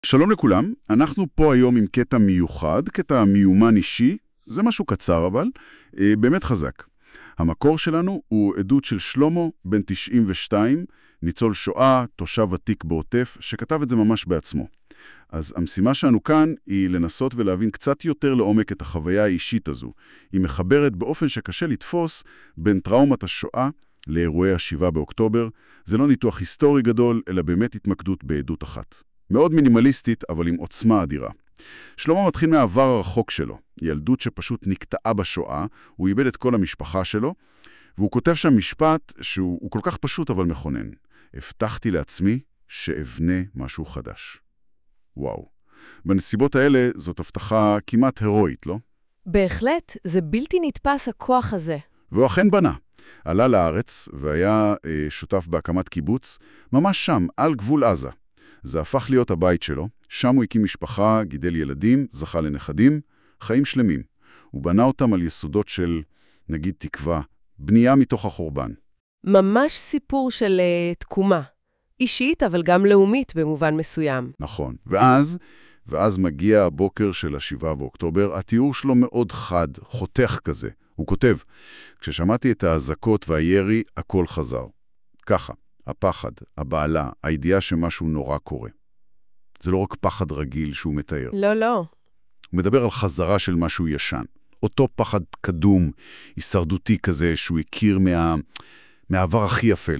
• הסיפור שלכם הופך לתוכנית רדיו אישית
זו הדרך שבה סיפור החיים יישמע - שני קריינים מקצועיים מספרים את הסיפור כמו תוכנית רדיו מרגשת